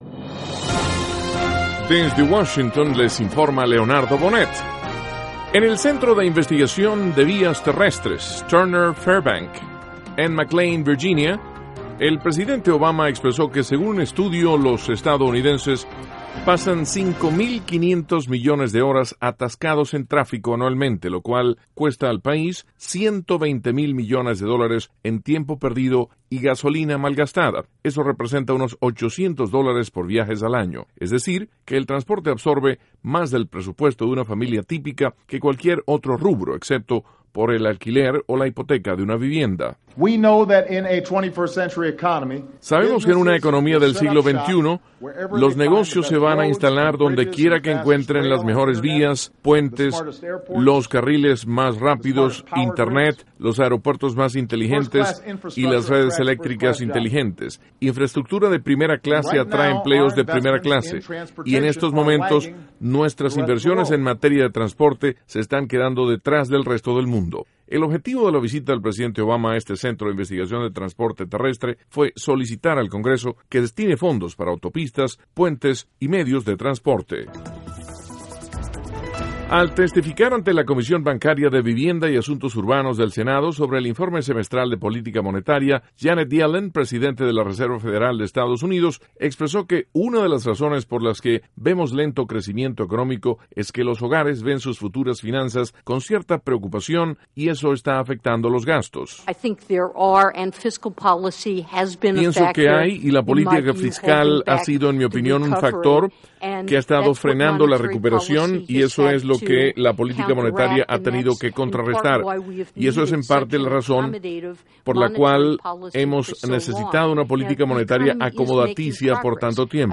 NOTICIAS - MARTES, 15 DE JULIO, 2014